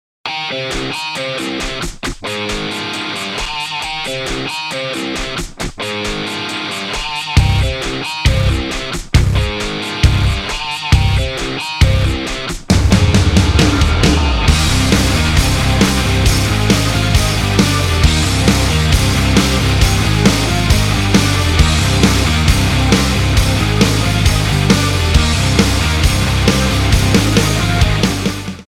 • Качество: 320, Stereo
гитара
громкие
без слов
нарастающие
барабаны
метал
Крутой драйвовый рингтон для любителей рока